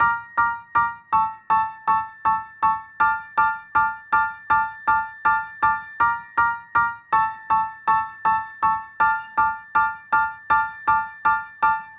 原声钢琴 80bpm Dminor
描述：D小调的原声钢琴循环曲...
Tag: 80 bpm Hip Hop Loops Piano Loops 2.02 MB wav Key : D